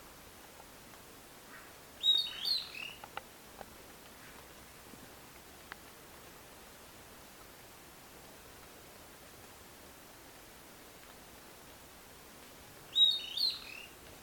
Southern Antpipit (Corythopis delalandi)
Life Stage: Adult
Location or protected area: Bio Reserva Karadya
Condition: Wild
Certainty: Observed, Recorded vocal